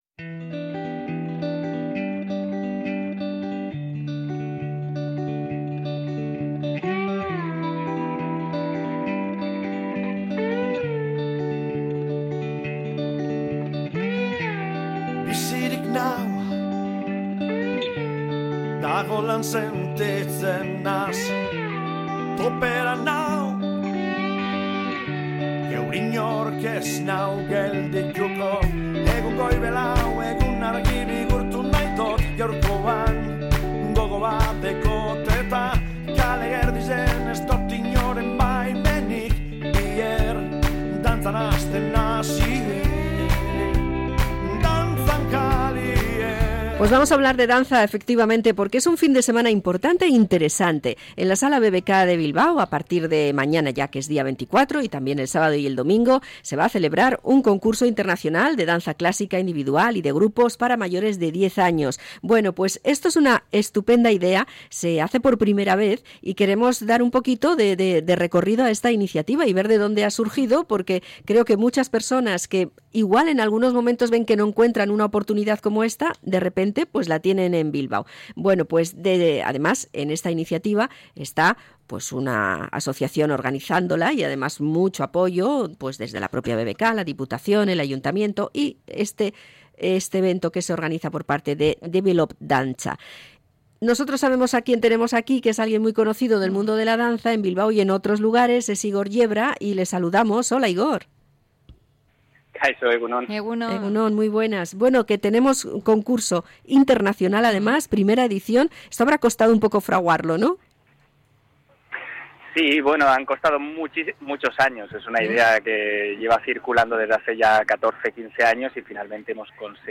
Entrevista al bailarín Igor Yebra por el I Concurso Internacional Developpe Dantza